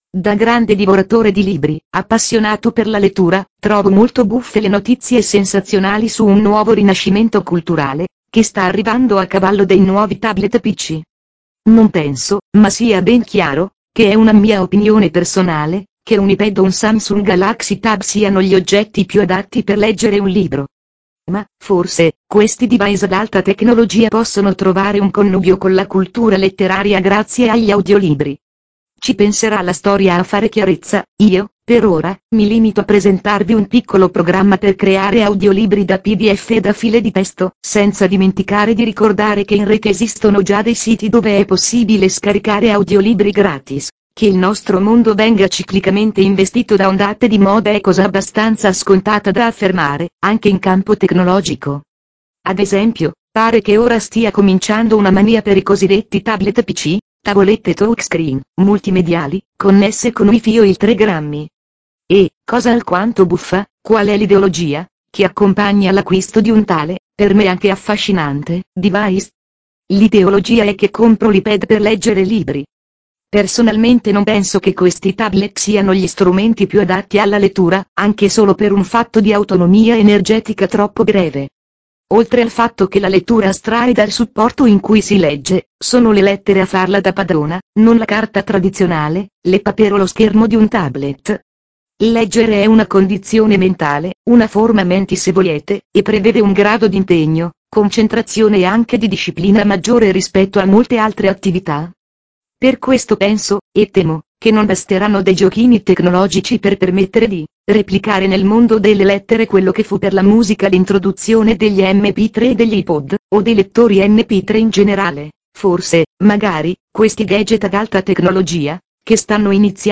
Per chi fosse curioso, potete scaricare questo articolo convertito tramite il programma: